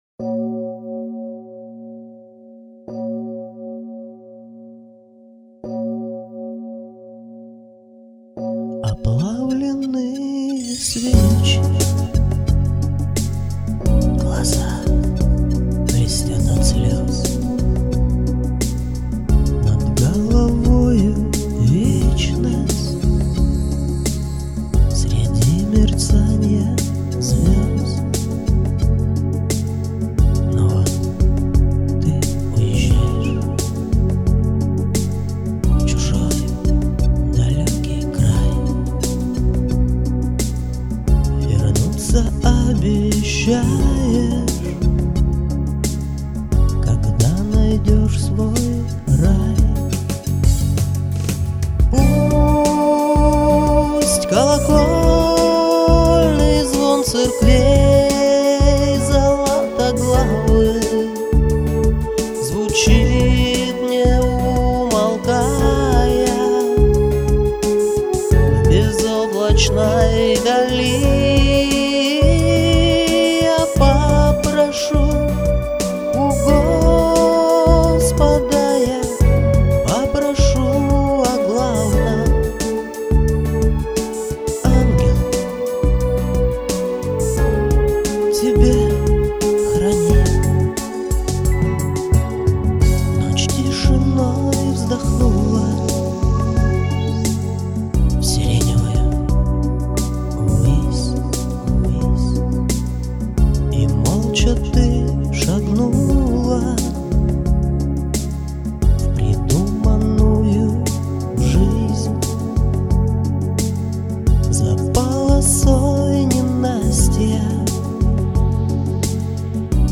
По звучанию импровизации и исполнения песня самодеятельная.